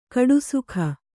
♪ kaḍusukha